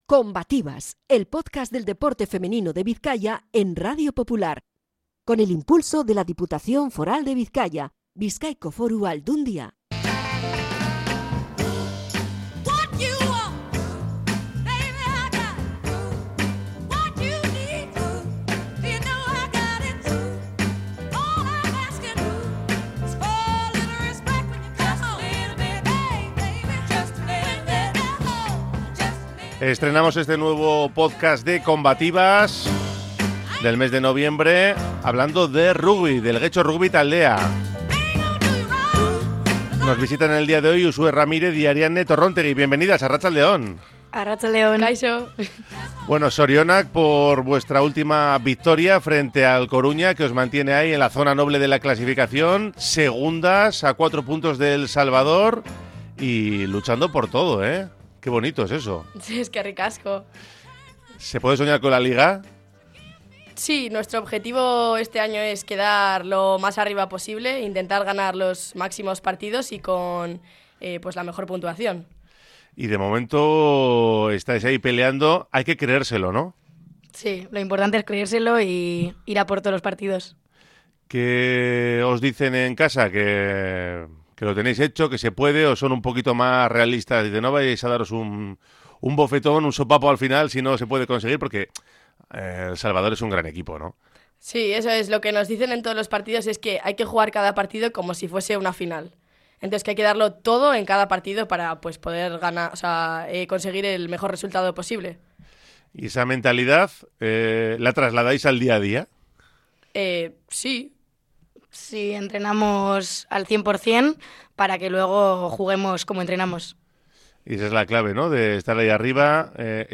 Entrevista con las jugadoras del Getxo Rugby